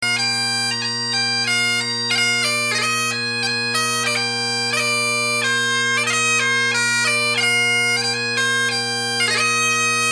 a Medallist pipe chanter with Heritage drones
4/4 march: